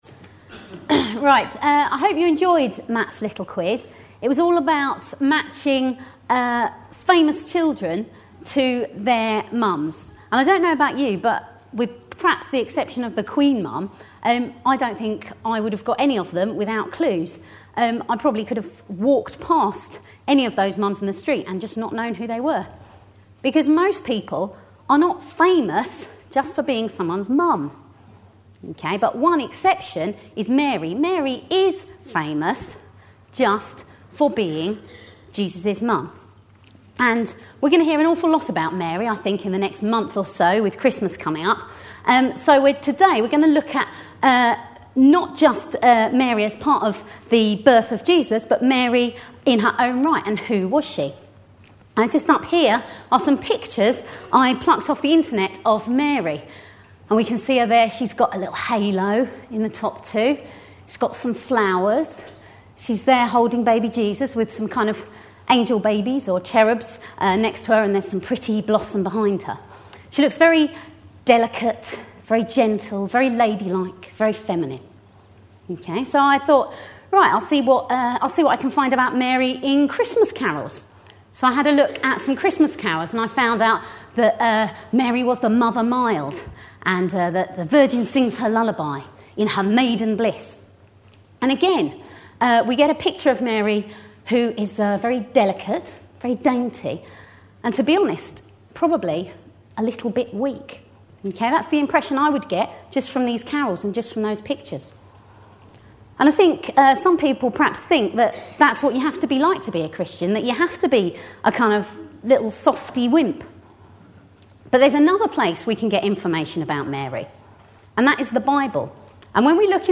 A sermon preached on 21st November, 2010.